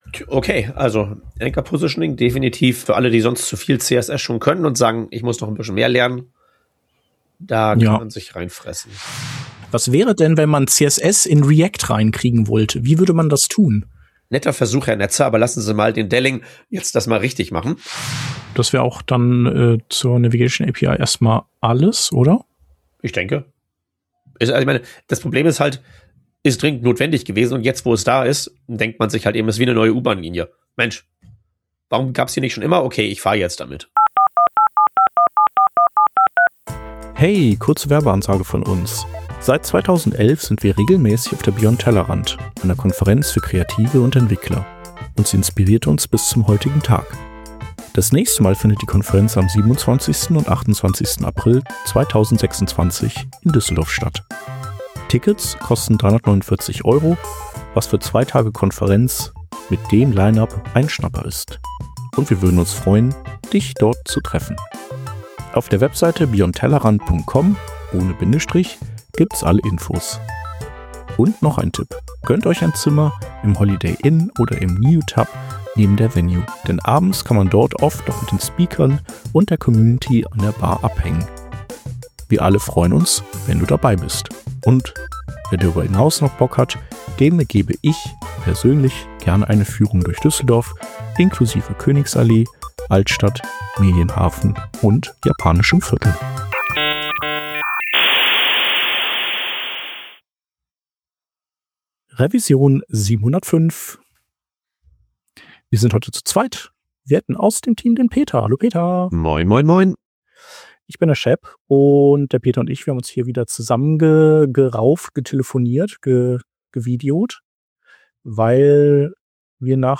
Working Draft ist ein wöchentlicher News-Podcast für Webdesigner und Webentwickler